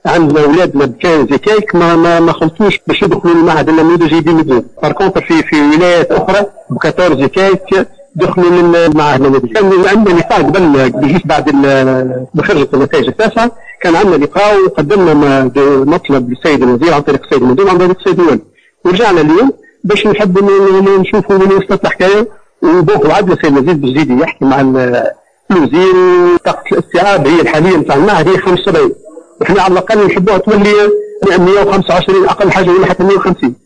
كما تحدث أحدهم لمراسلتنا